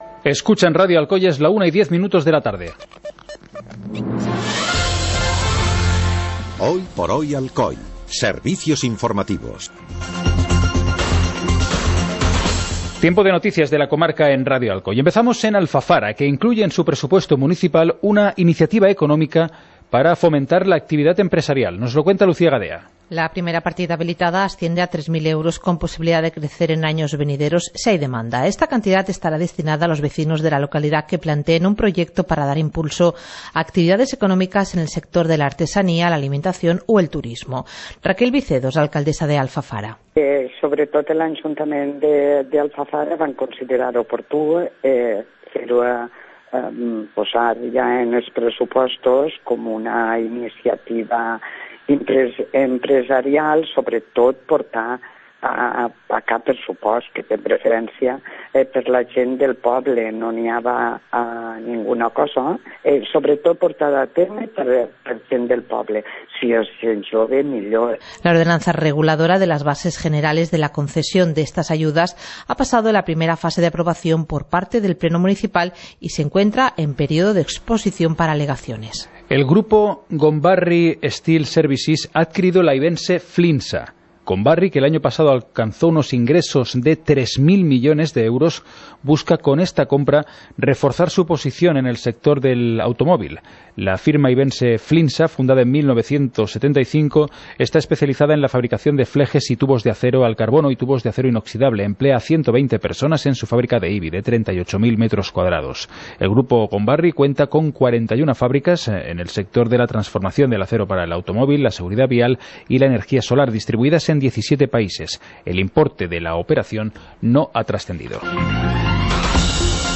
Informativo comarcal - jueves, 12 de abril de 2018